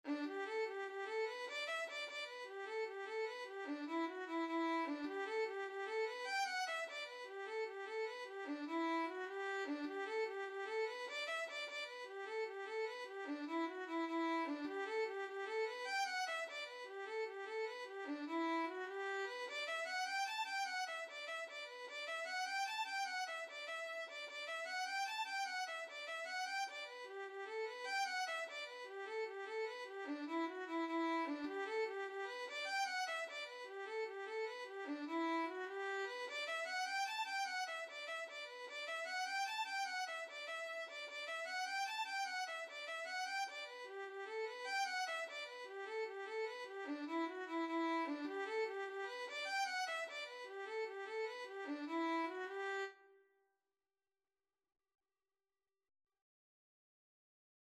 6/8 (View more 6/8 Music)
G major (Sounding Pitch) (View more G major Music for Violin )
Violin  (View more Intermediate Violin Music)
Traditional (View more Traditional Violin Music)
Irish
on298_st_patricks_day_VLN.mp3